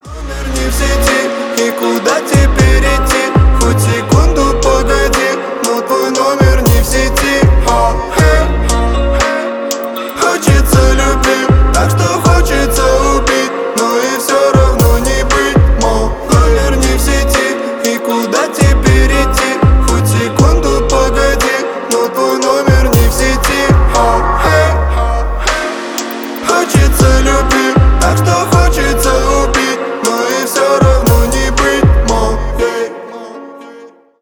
Поп Музыка
грустные # тихие